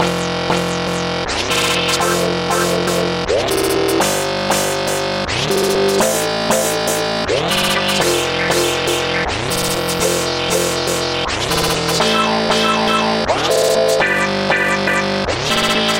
描述：Pizzi风格的合成器，带有方形和锯齿堆叠的振荡器。LFO用于控制LP切割的峰值调制。有轻微的突变。除了方形振荡器外，其他都添加了合唱效果。
Tag: 120 bpm Dance Loops Synth Loops 2.69 MB wav Key : C